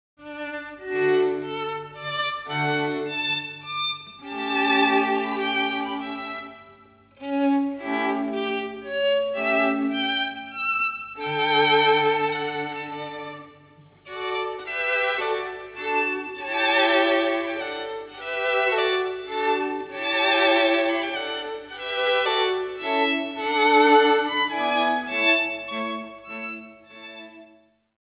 first violin
second violin
viola
cello)hu